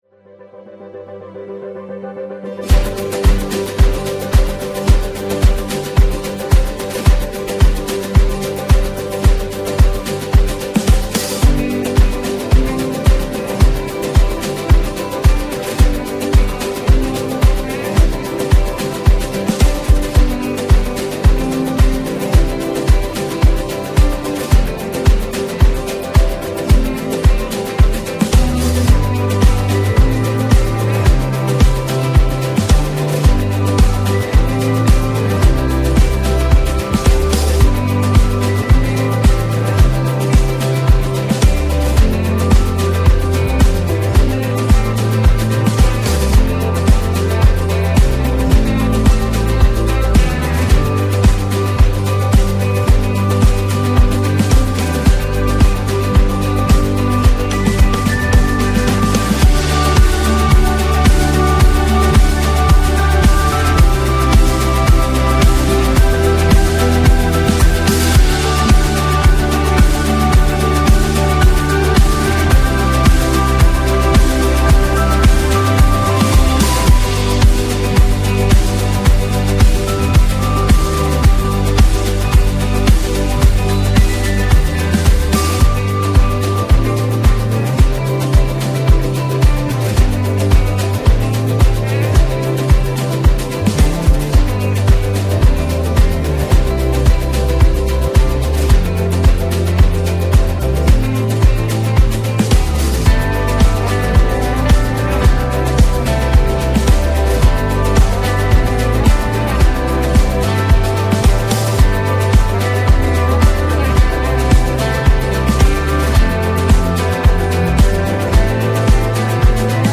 Baptisms from the February 2026 baptism service at the Peoria Campus.